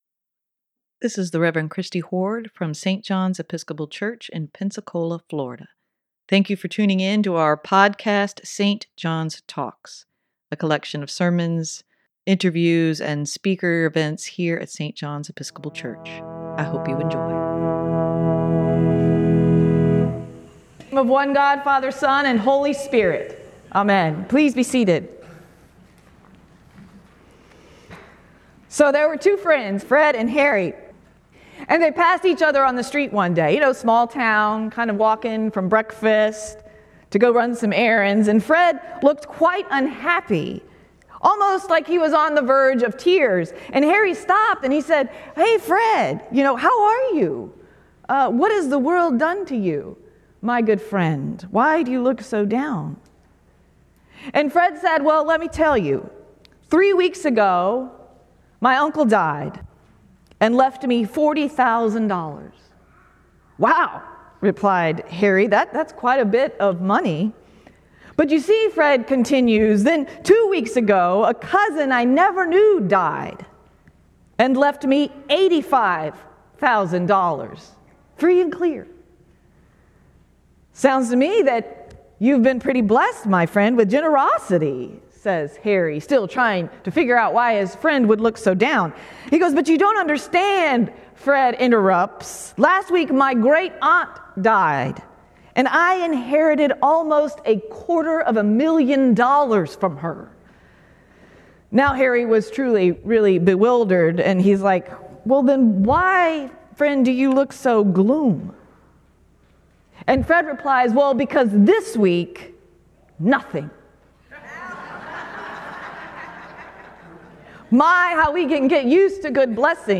Sermon for Oct. 1, 2023: Our God is a God of abundance - St. John's Episcopal Church
sermon-10-1-23.mp3